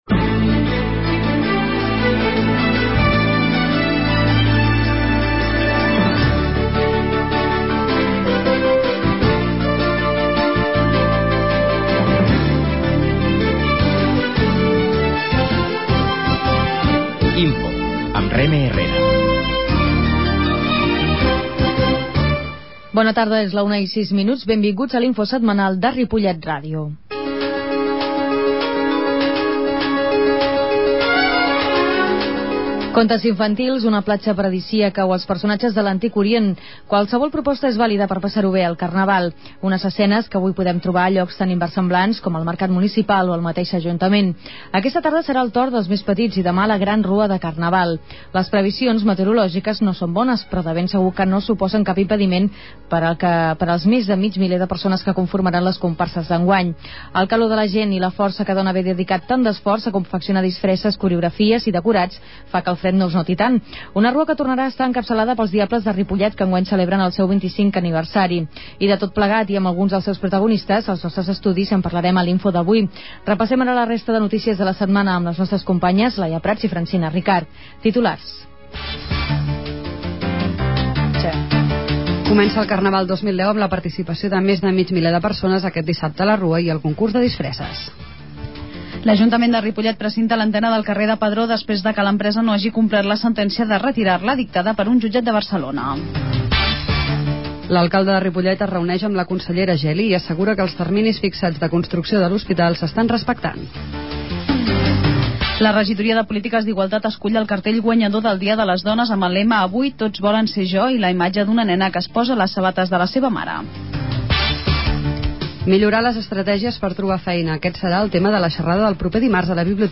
La qualitat de so ha estat redu�da per tal d'agilitzar la seva desc�rrega.